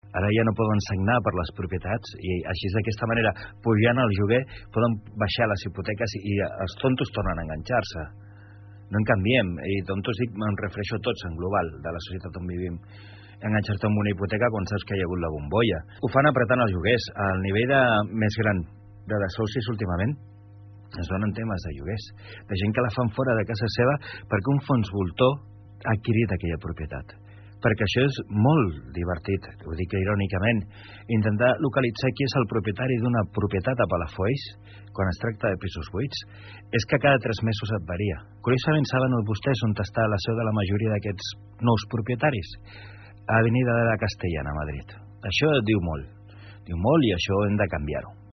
A l’entrevista, entre d’altres, Osorio també va fer referència a la gestió de la Tordera, que va reivindicar com a patrimoni natural de la zona i que creu que cal deixar de maltractar, o també sobre la gestió dels boscos del municipi, en estat de deixadesa i que suposen un perill per l’elevat risc d’incendis.